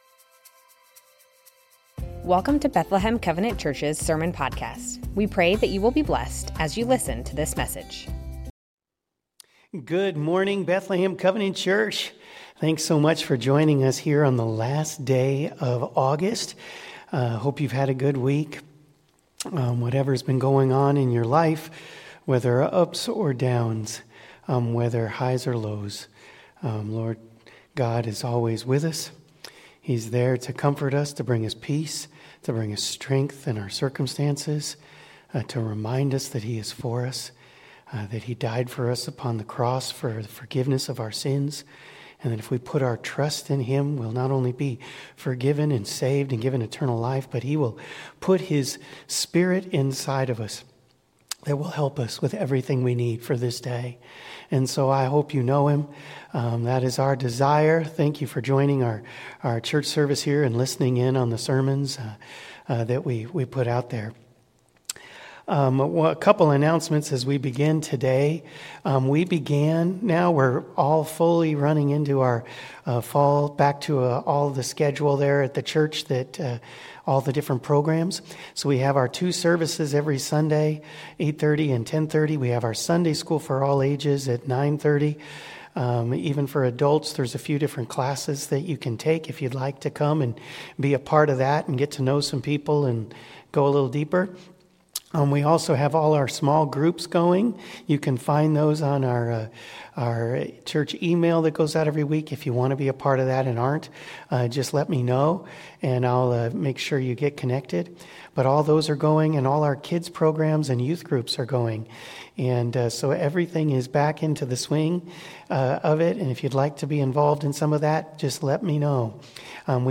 Bethlehem Covenant Church Sermons The Names of God - El Shaddai Aug 31 2025 | 00:33:27 1x 00:00 / 00:33:27 Subscribe Share Spotify RSS Feed Share Link Embed